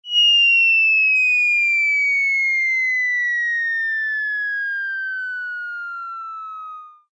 cogbldg_drop.ogg